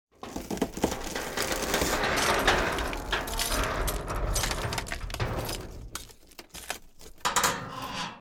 DayZ-Epoch/SQF/dayz_sfx/search/wreck_HMMWV_0.ogg at 2d00a515cd828221c080499f86a2ec955f4ae4f7
wreck_HMMWV_0.ogg